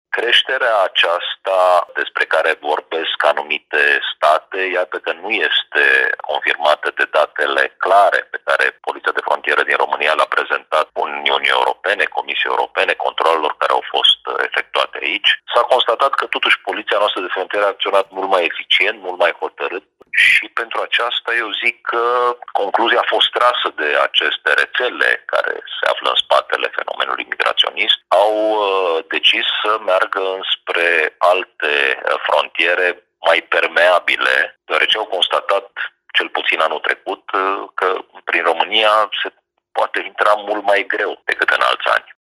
Subprefectul Ovidiu Drăgănescu spune că datele arată și că granițele sunt mai sigure.